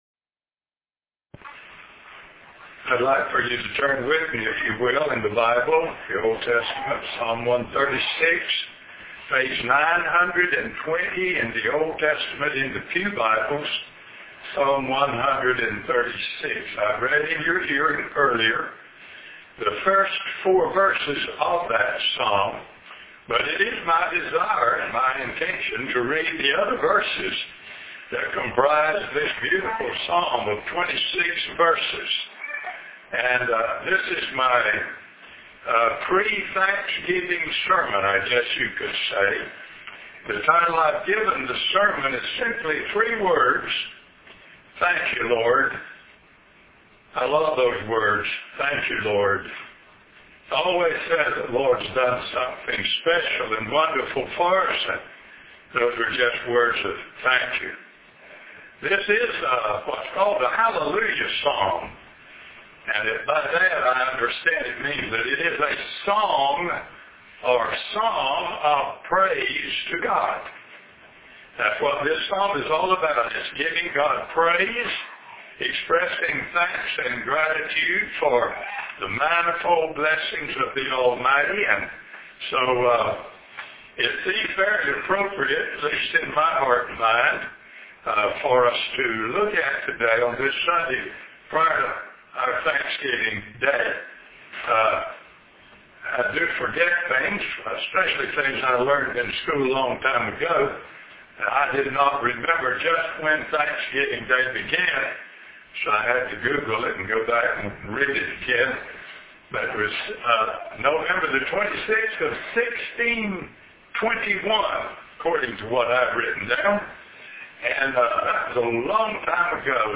Dec 6 In: Sermon by Speaker